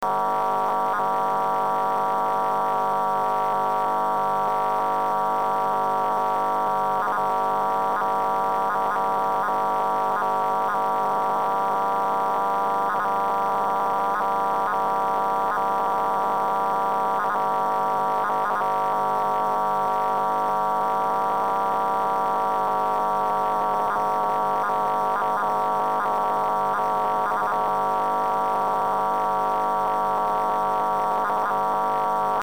ж). Inter (Франция) - частота 163,84 кГц, довольно мощный (все уши прожужжал).
Пример сигнала.